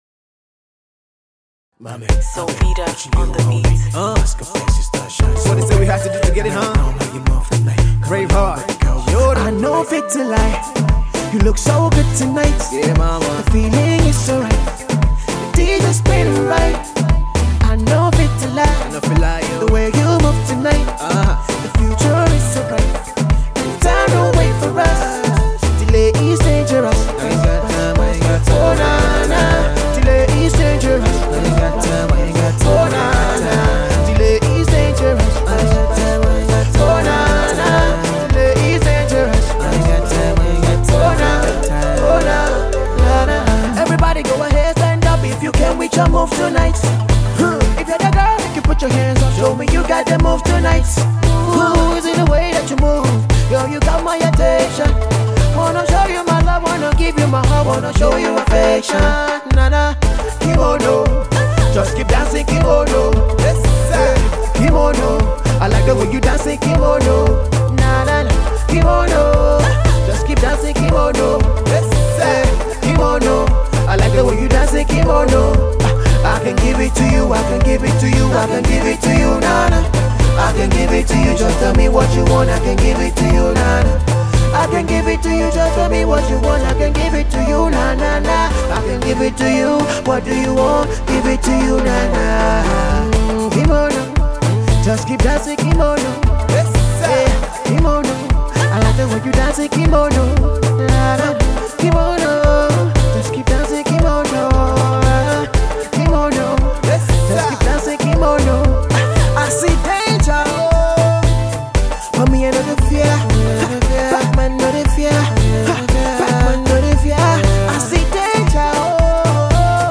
Alternative Pop
An up-tempo dance track
African rhythm.